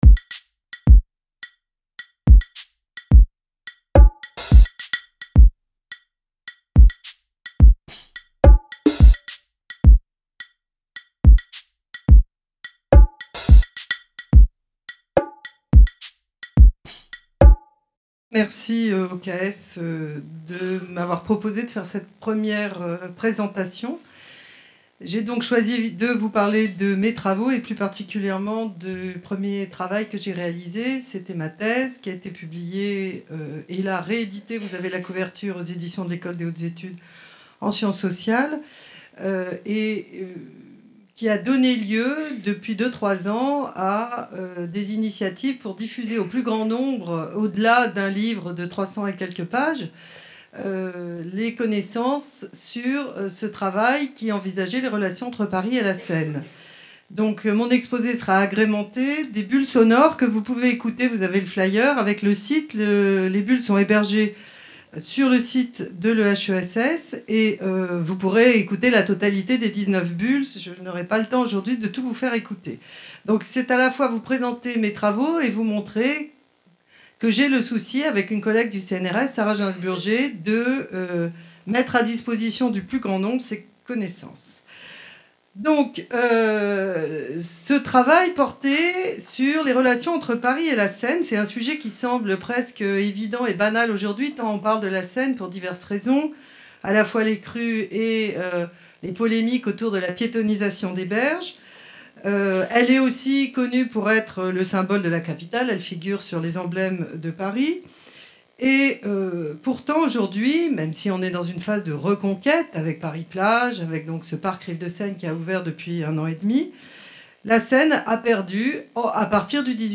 Il s’agit d’exposés proposés par des enseignants-chercheurs de l’Ecole sur leurs travaux.